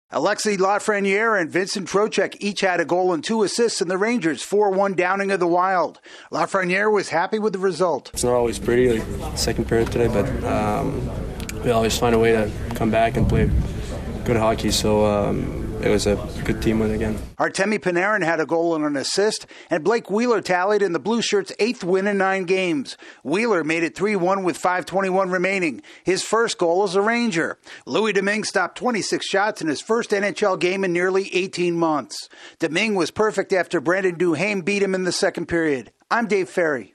The Rangers add another victory to their hot stretch. AP correspondent